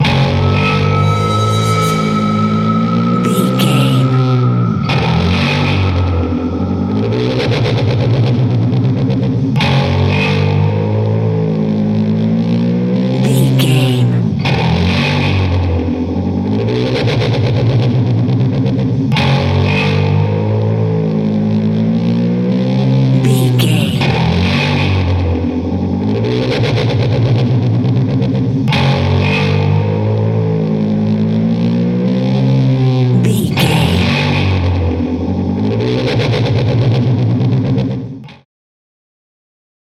Guitar Horror Hit.
Atonal
scary
tension
ominous
dark
haunting
eerie
electric guitar
synth
pads